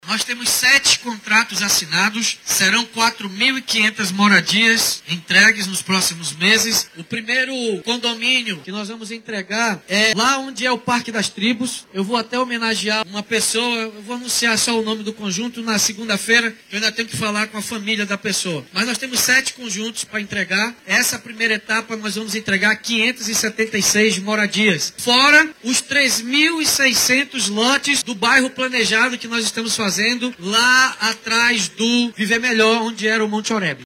SONORA-2-PREFEITO-DAVI-ALMEIDA.mp3